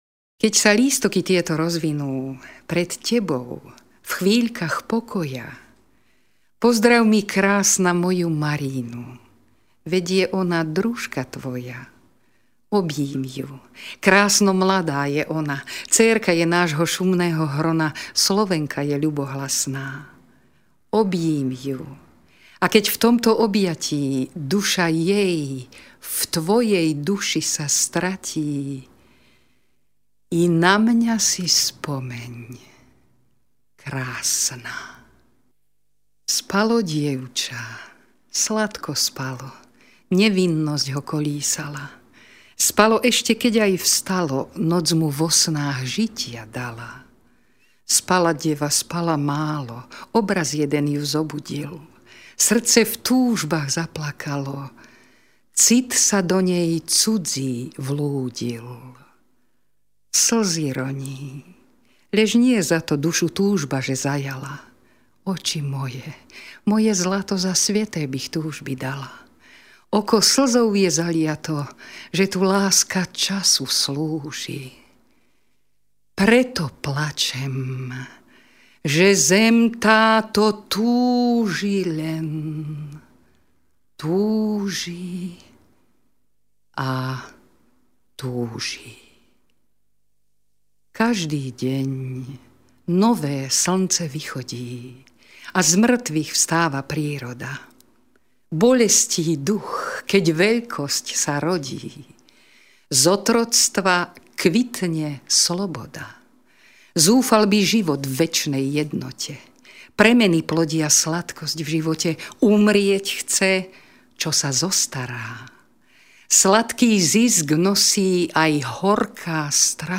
Úryvok z Maríny Andreja Sládkoviča v podaní pani Evy Kristinovej: